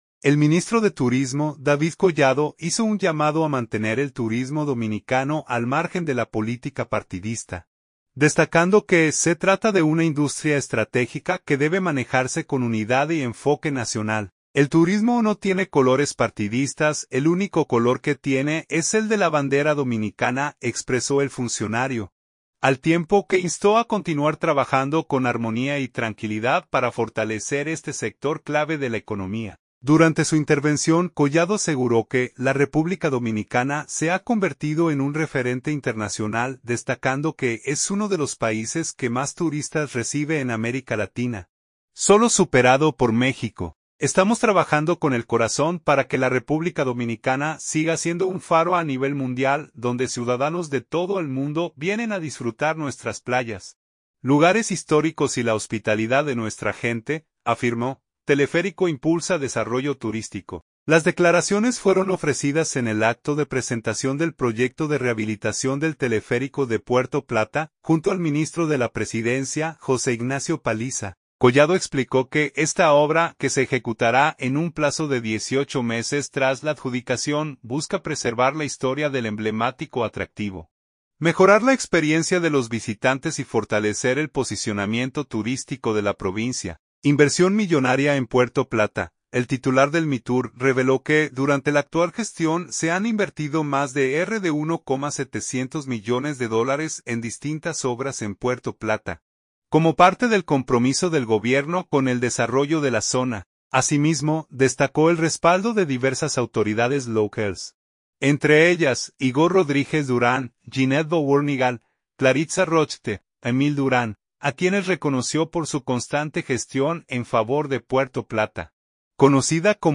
Las declaraciones fueron ofrecidas en el acto de presentación del proyecto de rehabilitación del Teleférico de Puerto Plata, junto al ministro de la Presidencia, José Ignacio Paliza.